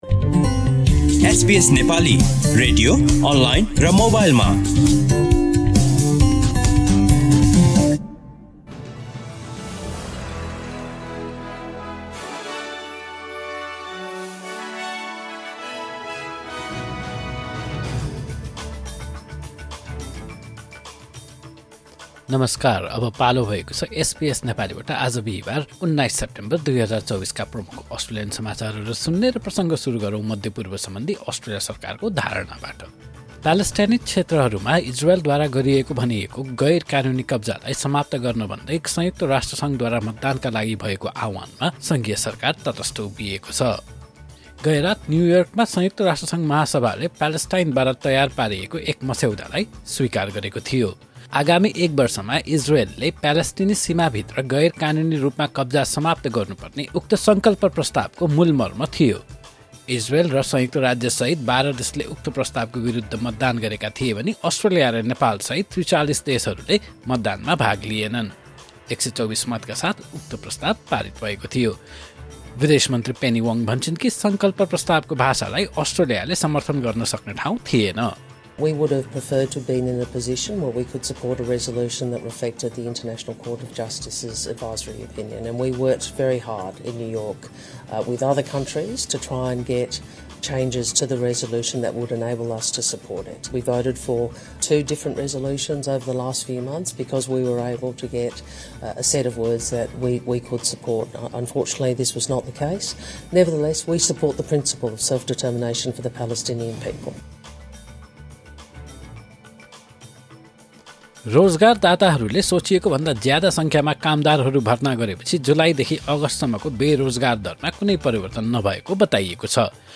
SBS Nepali Australian News Headlines: Thursday, 19 September 2024